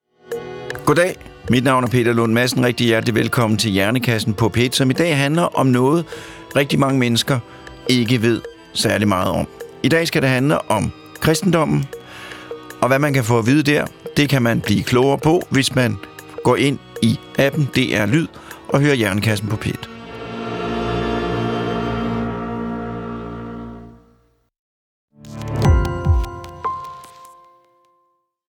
Vi har eksperter i studiet.